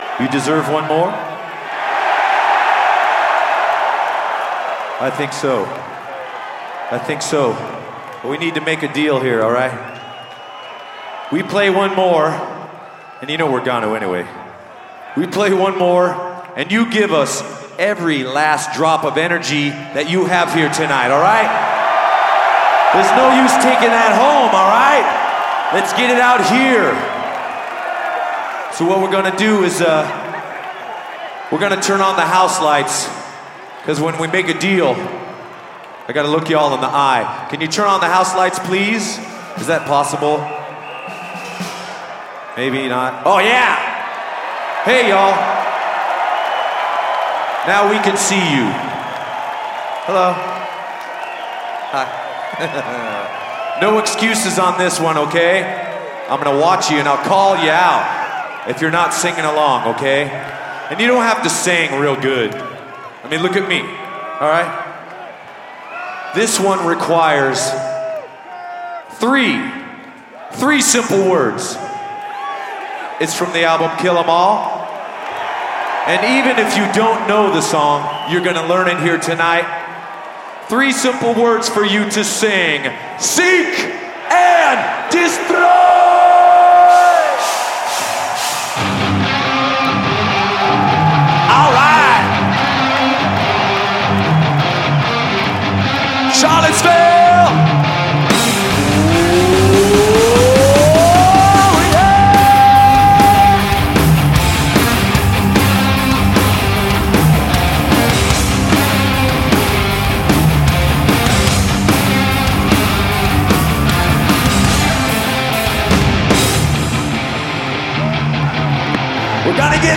(live), Charlottesville, Virginia